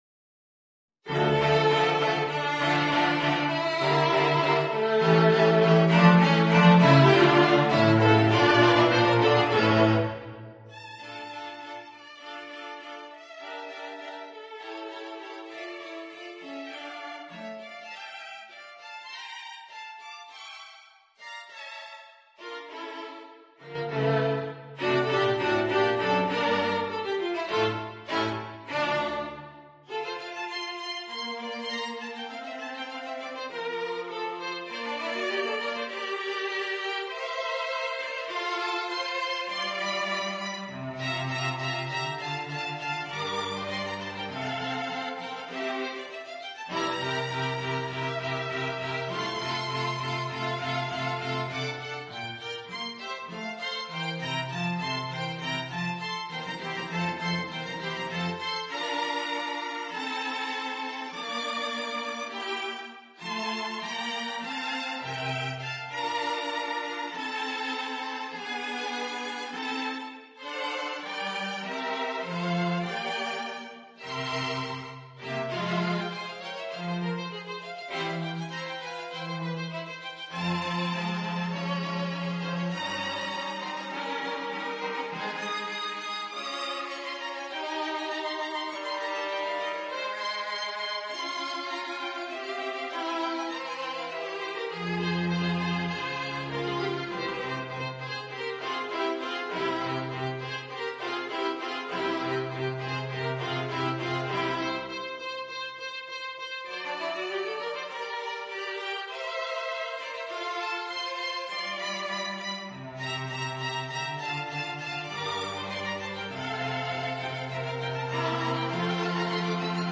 Instrument: String Quartet
Style: Classical
haydn_string_quartet_op76_2_STRQ.mp3